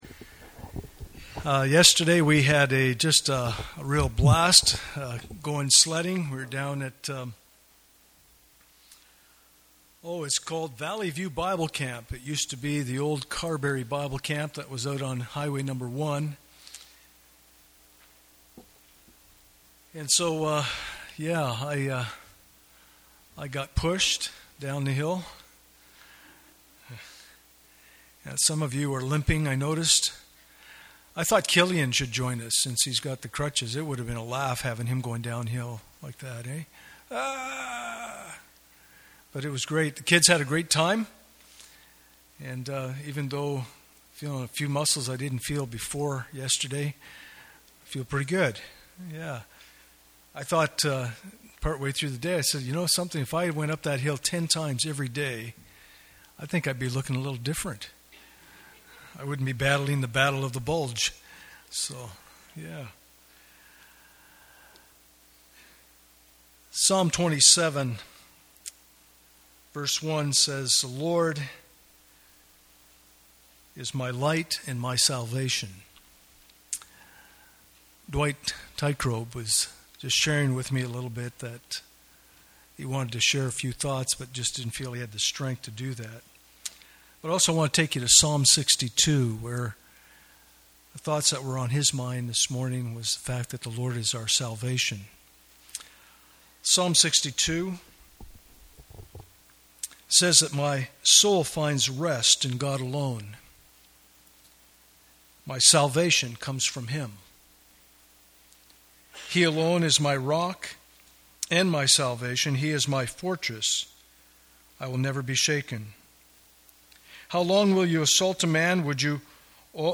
Living in an Eroding Culture Passage: 1 Peter 4:12-19 Service Type: Sunday Morning « Communion Service Why Should God Give Ear to My Words?